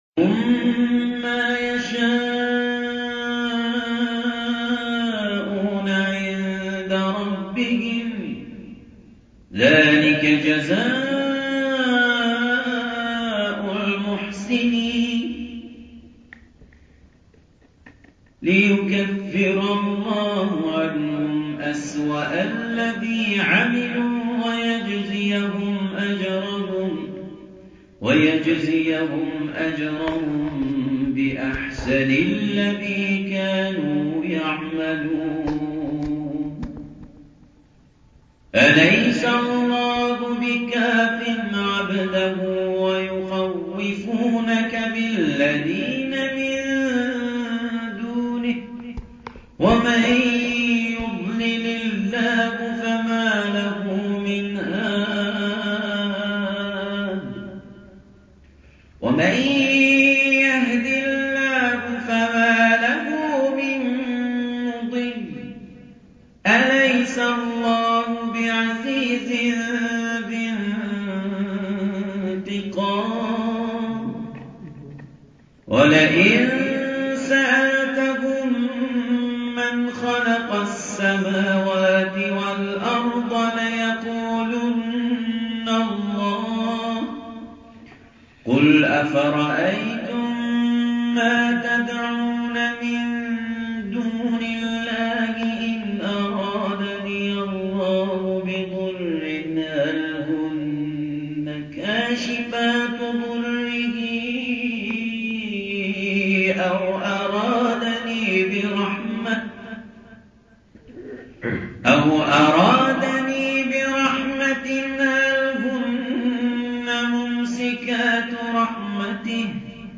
شبکه اجتماعی: فراز‌هایی صوتی از تلاوت قاریان ممتاز کشور ارائه می‌شود.